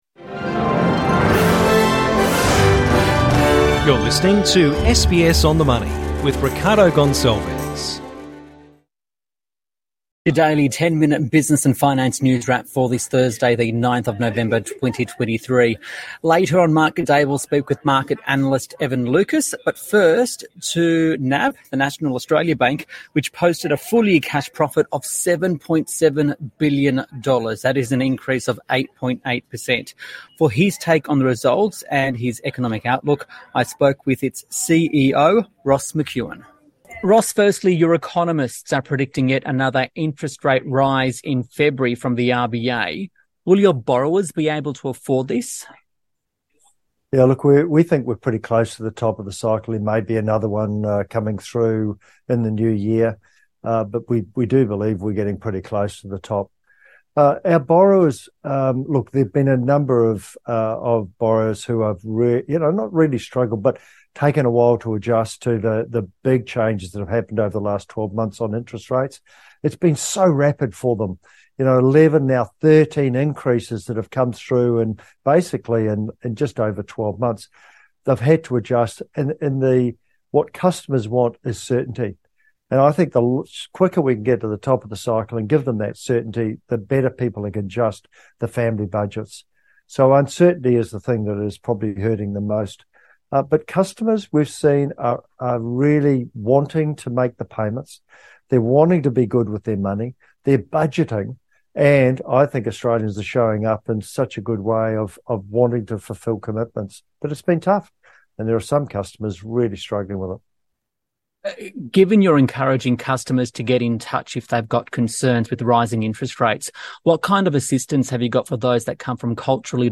SBS On the Money special interview: NAB CEO Ross McEwan on rates, mortgages and Optus outage